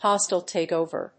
意味・対訳 ホスタイルテークオーバー